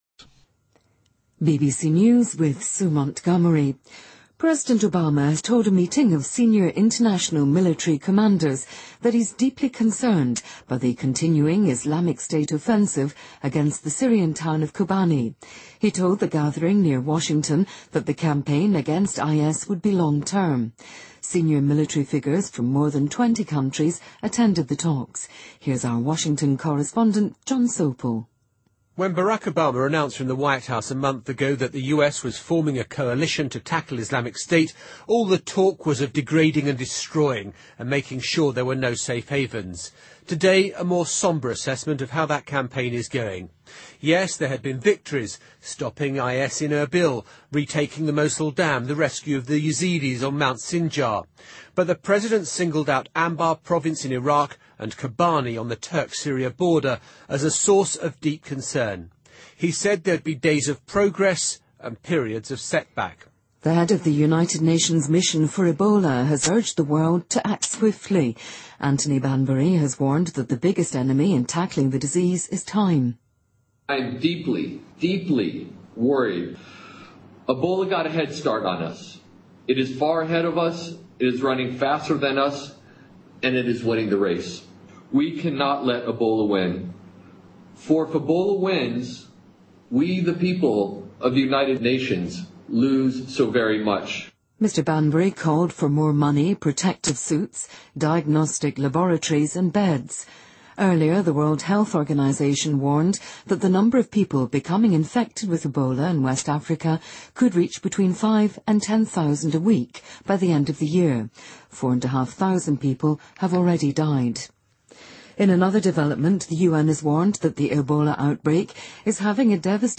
BBC news,澳大利亚作家理查德·弗拉纳根获2014布克奖